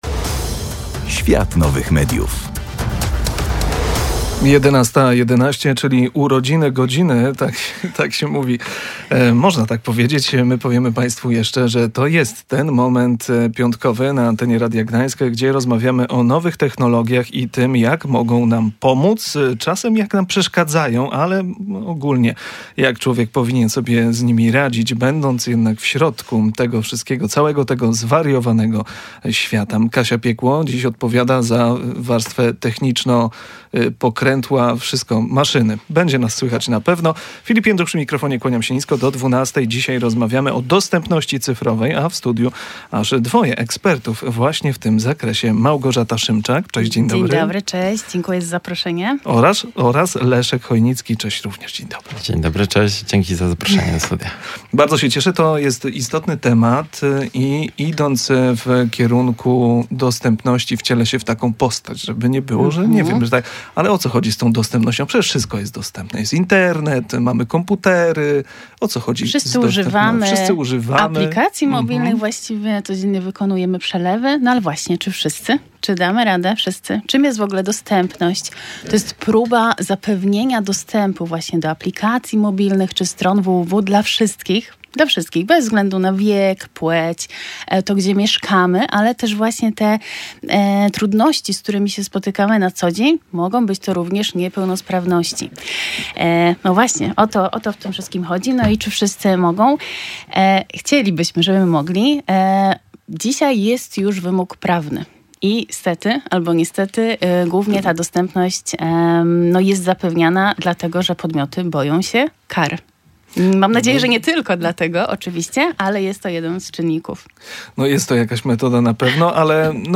Podczas spotkania zaprezentowaliśmy fascynujące możliwości sztucznej inteligencji, przeprowadzając na żywo test aplikacji Be My Eyes, która z ogromną precyzją opisała nasze studio.